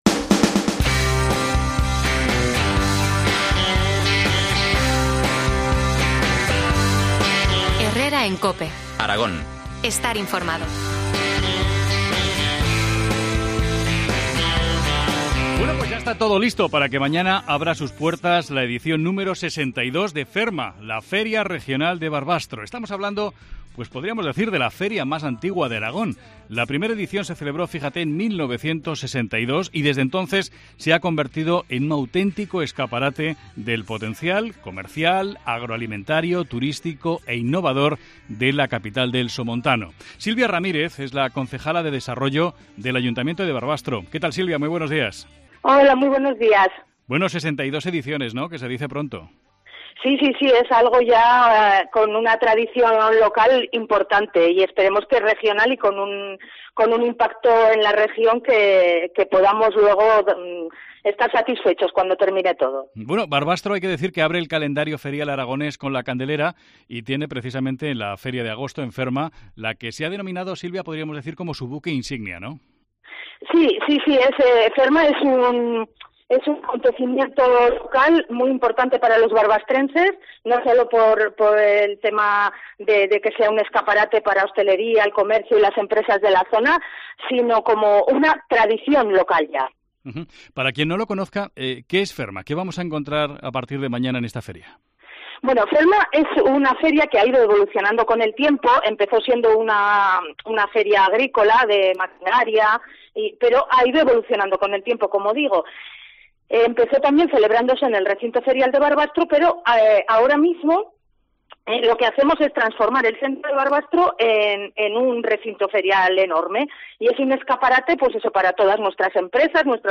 AUDIO: Entrevista a Silvia Ramírez, concejala de Desarrollo del Ayuntamiento de Barbastro, sobre FERMA 2023